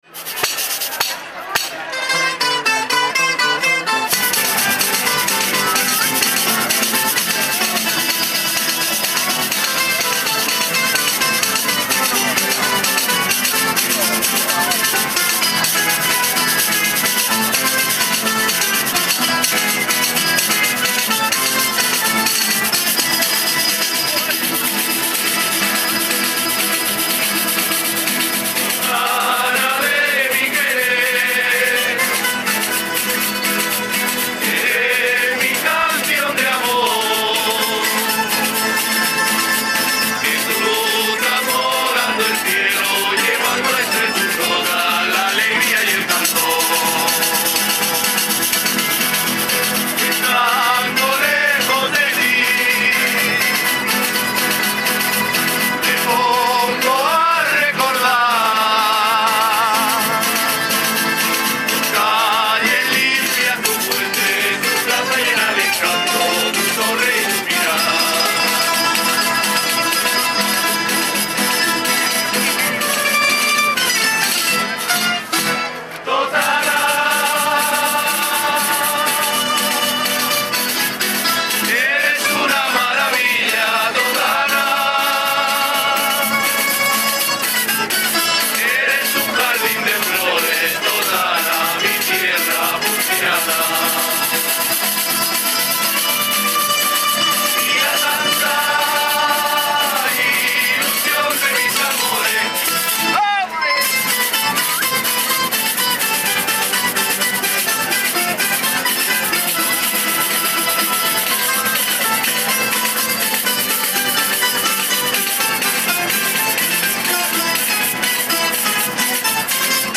Romería Santa Eulalia Totana 08/12/2015 - Reportaje I
Desde la madrugada, muchas personas han ido accediendo a las inmediaciones de la ermita de La Santa, donde, han esperado, entre los cánticos de las cuadrillas, las horas previas al inicio de la bajada; y el gran número de personas congregadas se debió en gran parte a la buena climatología que acompañó desde bien temprano.
En "El Rulo", la patrona ha sido recibida, además de por la Banda Municipal, por una traca y fuegos artificiales y posteriormente ha sido trasladada a la ermita de San Roque.